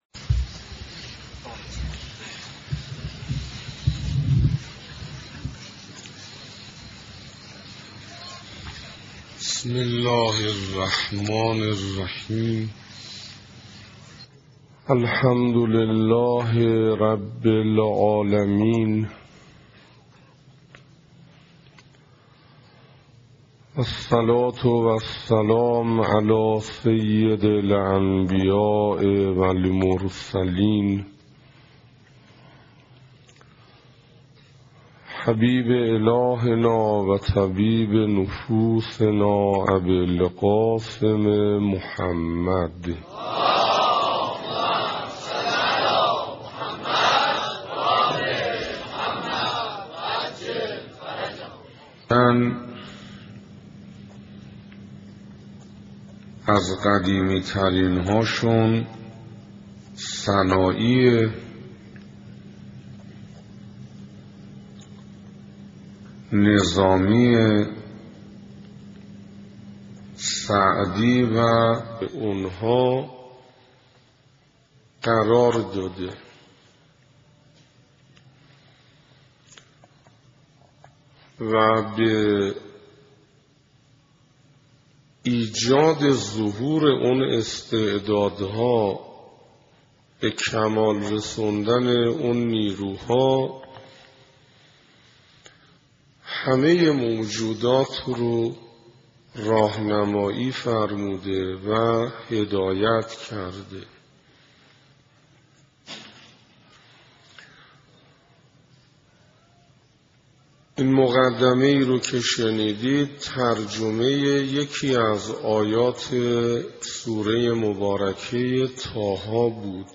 سخنراني دهم
صفحه اصلی فهرست سخنرانی ها ارزيابي ارزش انسان (2) سخنراني دهم (تهران حسینیه صاحب‌الزمان(عج)) محرم1428 ه.ق - بهمن1385 ه.ش دانلود متاسفم..